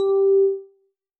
bell